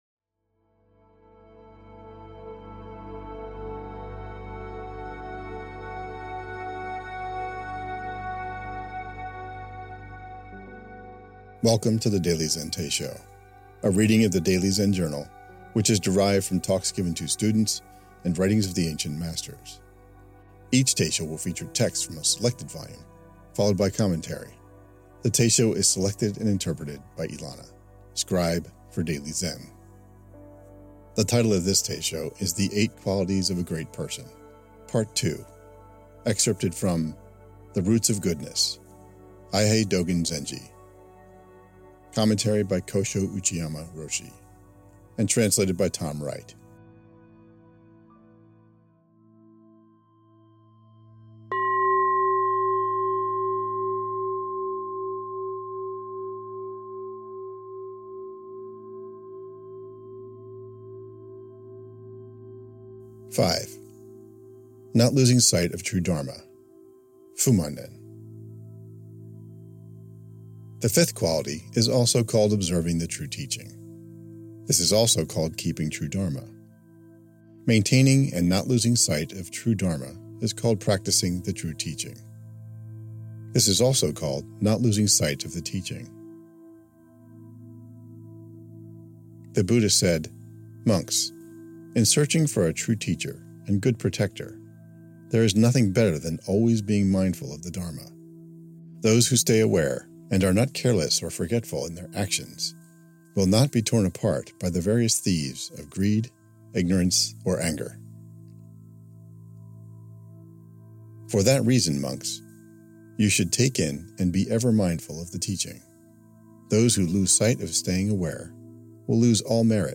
The Daily Zen Teisho The Eight Qualities of a Great Person – Part 2 Play Episode Pause Episode Mute/Unmute Episode Rewind 10 Seconds 1x Fast Forward 30 seconds 00:00 / 00:13:51 Download file | Play in new window | Duration: 00:13:51